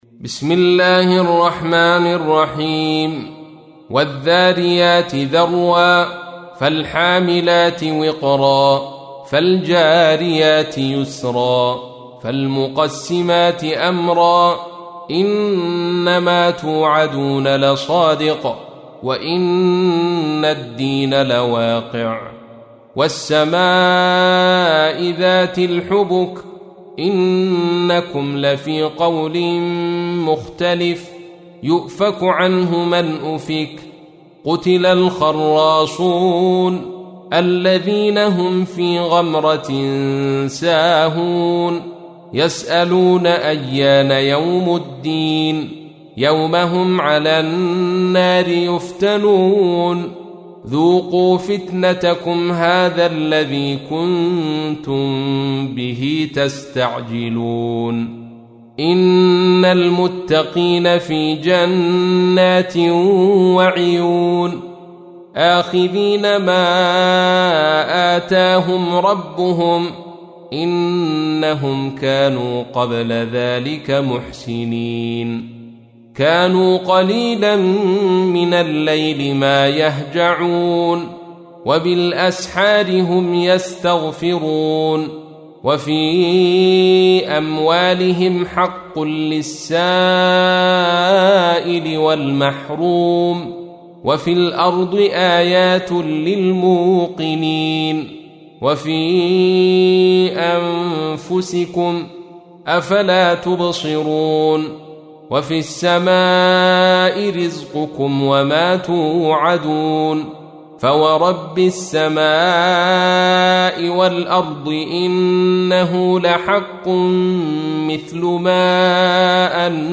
تحميل : 51. سورة الذاريات / القارئ عبد الرشيد صوفي / القرآن الكريم / موقع يا حسين